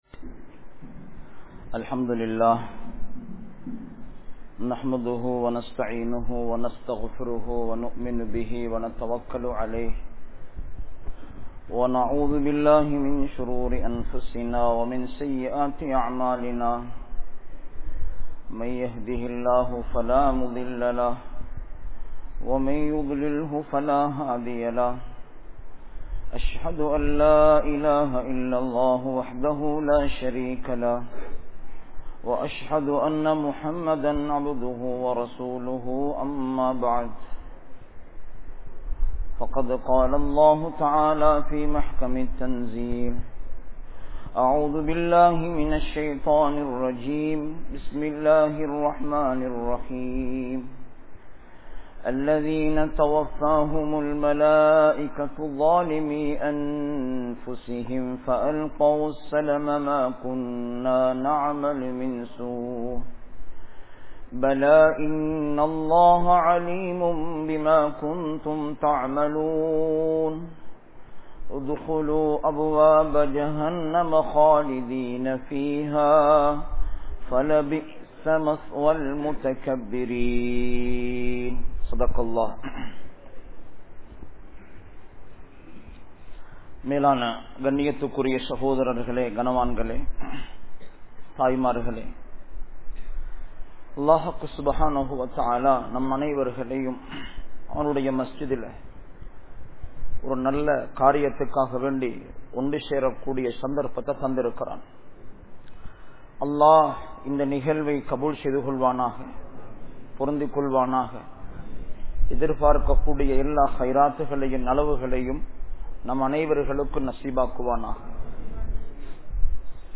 Duties of Parents & Children(பெற்றோர்களினதும் பிள்ளைகளினதும் கடமைகள்) | Audio Bayans | All Ceylon Muslim Youth Community | Addalaichenai